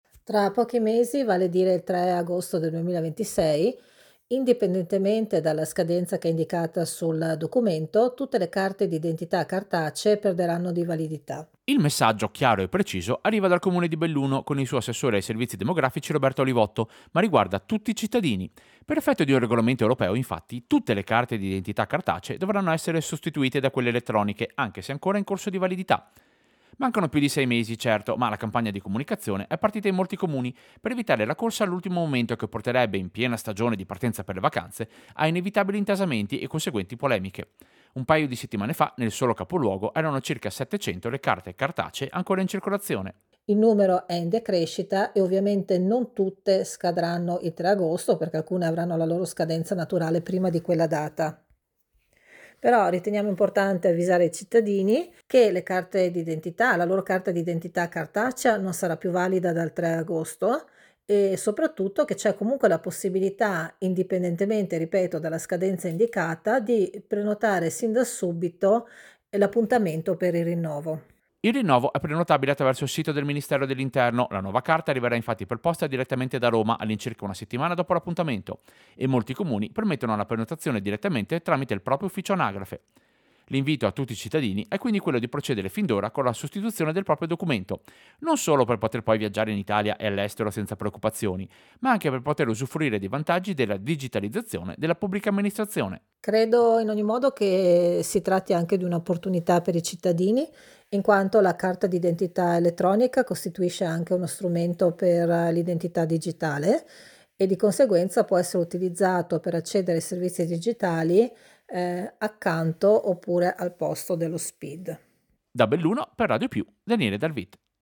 Servizio-Carte-identita-cartacee-in-scadenza.mp3